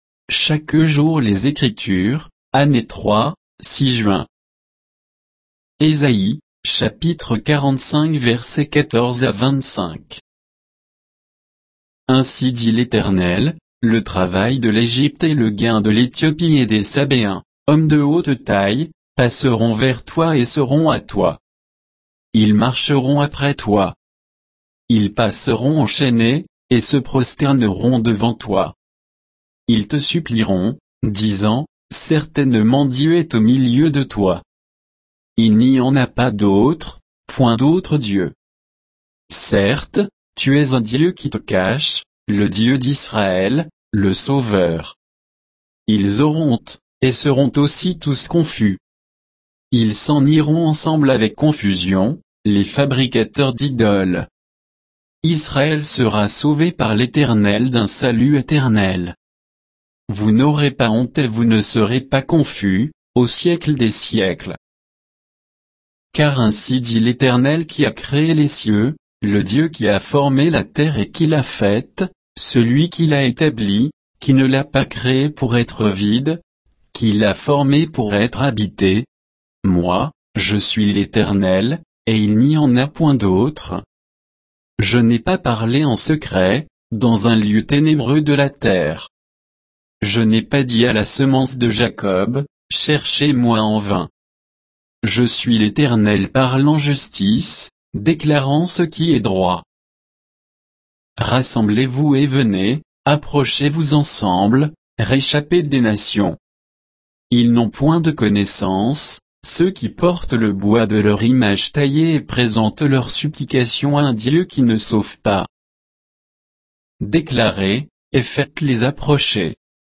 Méditation quoditienne de Chaque jour les Écritures sur Ésaïe 45